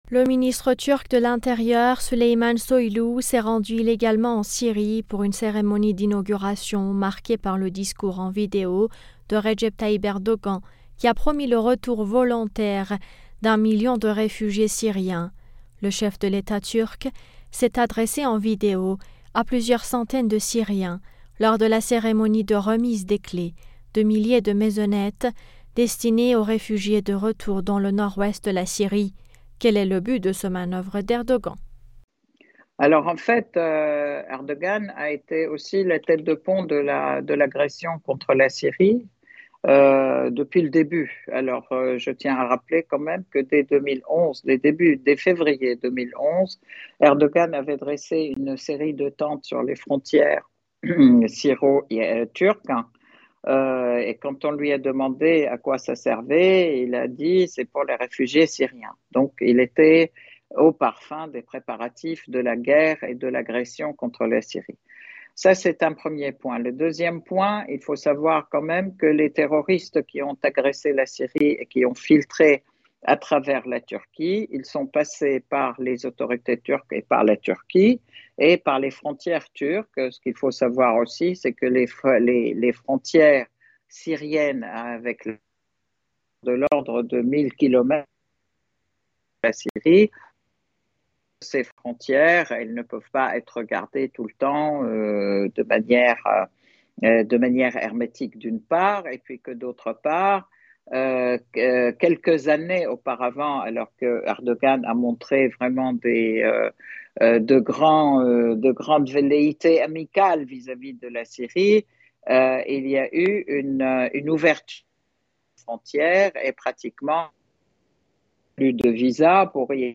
analyste franco-syrienne des questions internationales nous en dit plus.